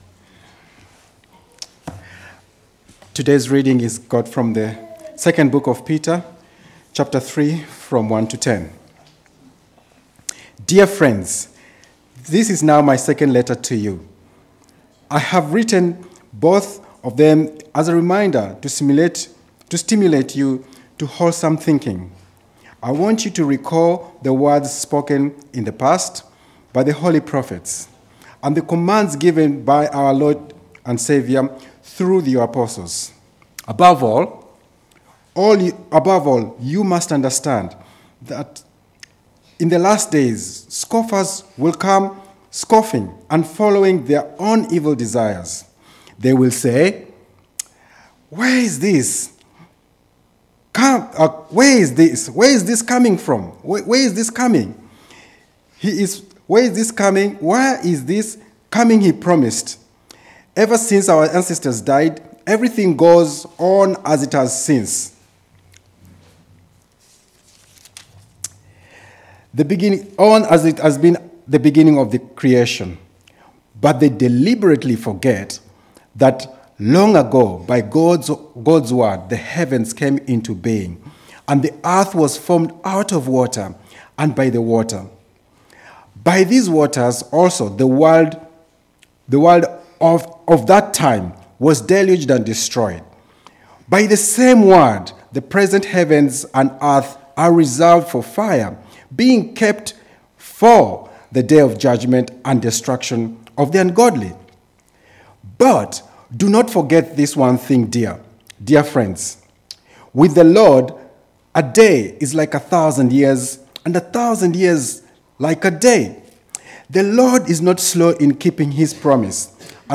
Remember These Things Passage: 2 Peter 3:1-10 Service Type: Weekly Service at 4pm « 2 Peter 1